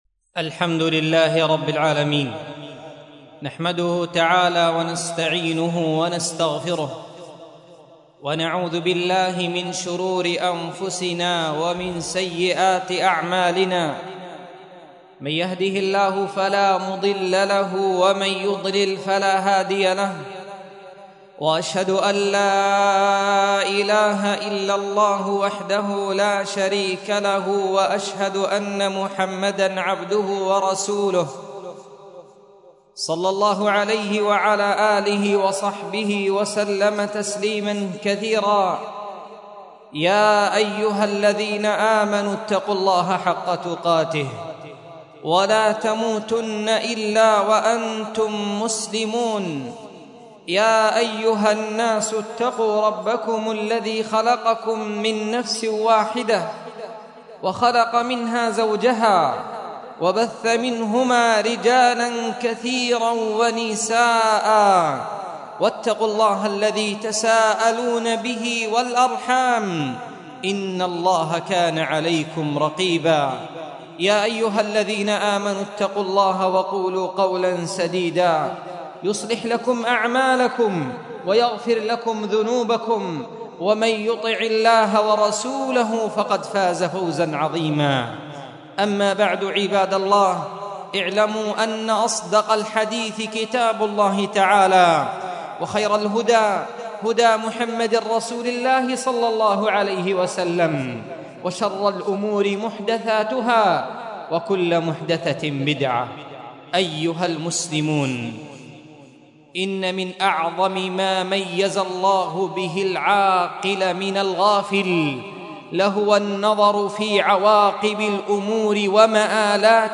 مسجد درة عدن محافظة عدن حرسها الله